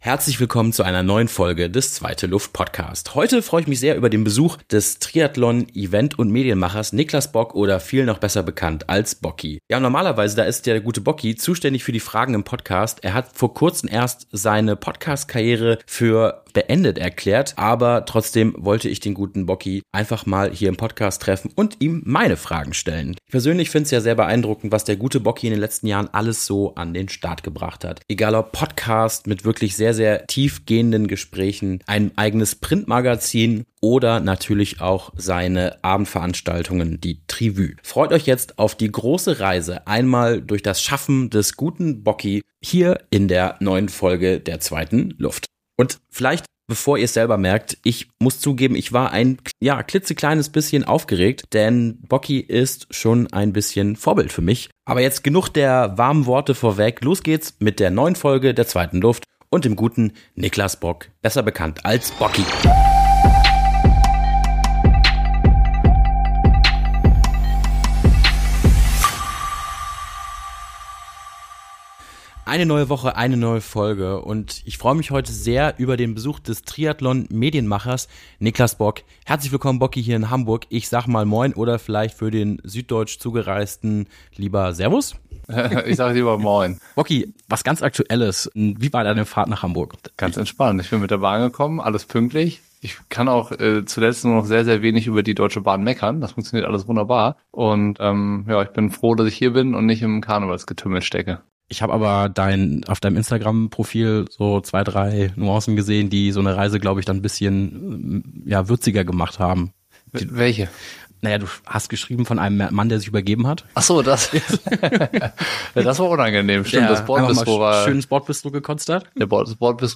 Ein ehrliches, tiefgehendes Gespräch über Leidenschaft, Medien, Eigenständigkeit und die Liebe zum Sport.